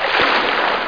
1 channel
water.mp3